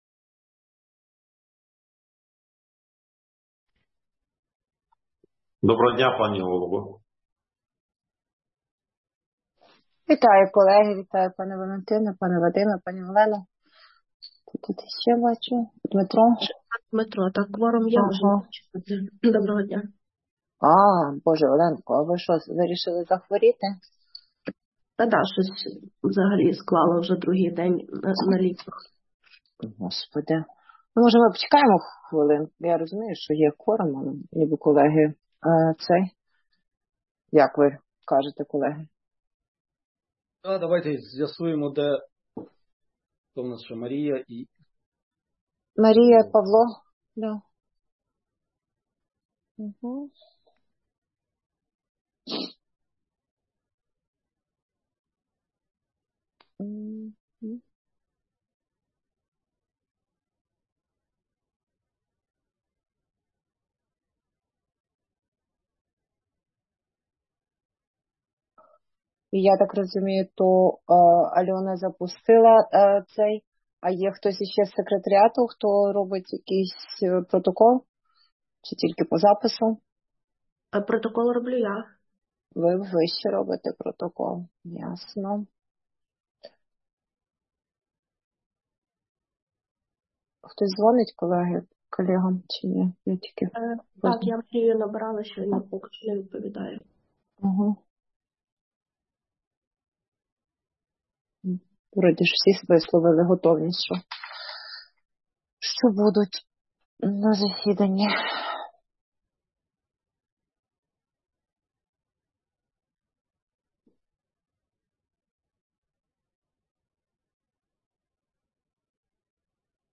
Аудіозапис засідання Комітету 7 січня 2024 року